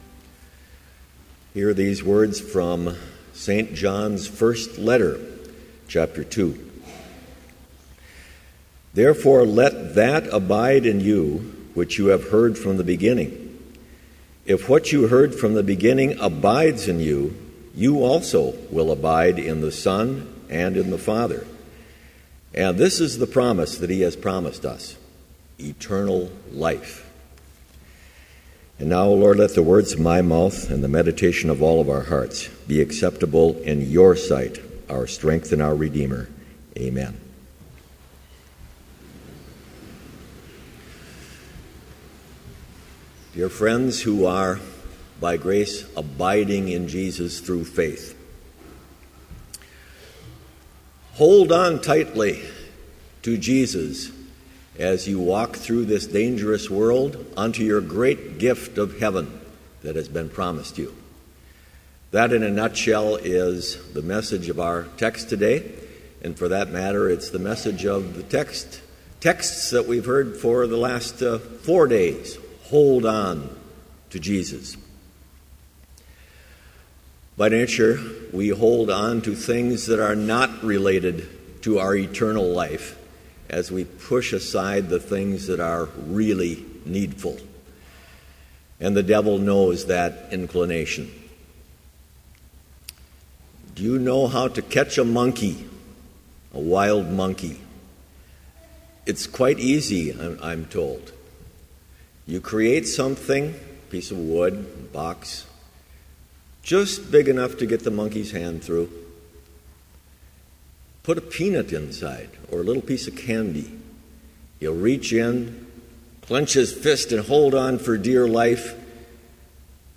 Complete service audio for Chapel - October 23, 2014